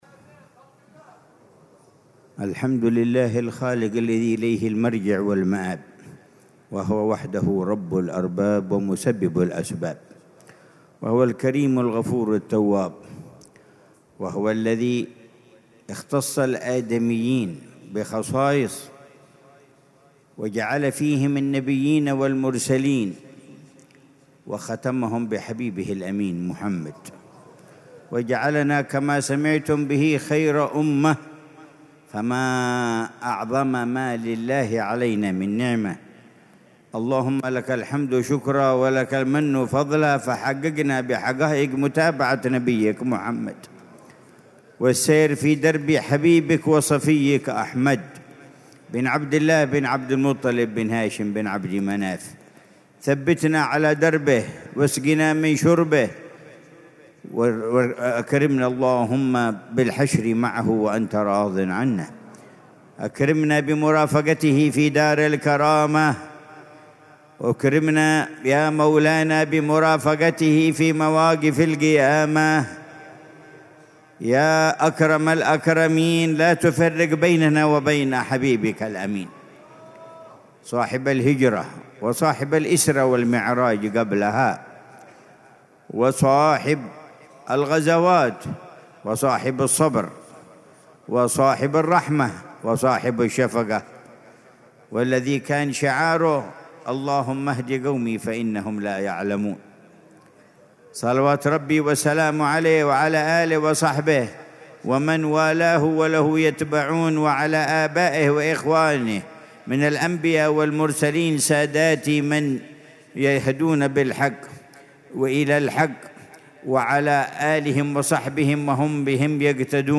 محاضرة العلامة الحبيب عمر بن محمد بن حفيظ ضمن سلسلة إرشادات السلوك، ليلة الجمعة 2 محرم 1447هـ في دار المصطفى بتريم، بعنوان: